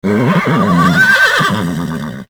Horse hee haw